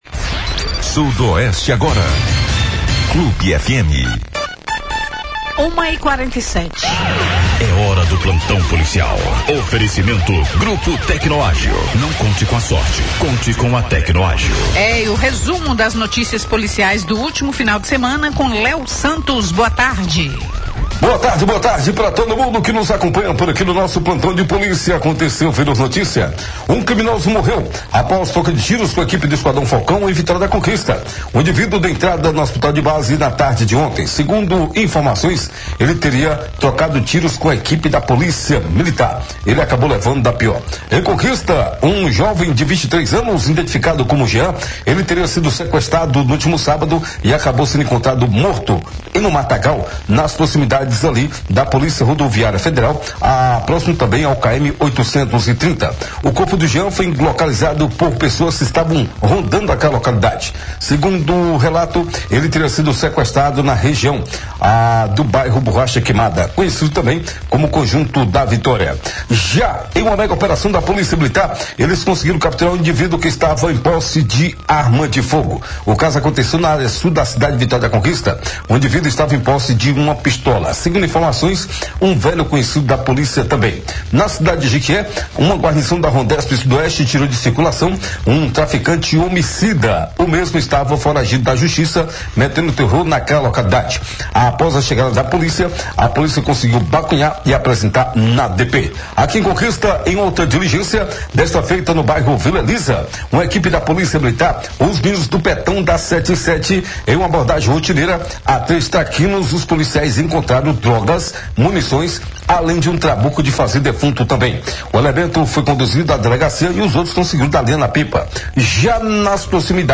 O Plantão Policial do Sudoeste Agora, na Rádio Clube de Conquista, desta segunda-feira (11), apresenta um resumo dos principais acontecimentos do final de semana. Um homem que teria sido sequestrado acabou vítima de um atentado fatal. Nas proximidades da Unidade de Saúde da Família Morada dos Pássaros, um suspeito morreu durante uma Operação Policial, após ser socorrido ao Hospital Geral de Vitória da Conquista.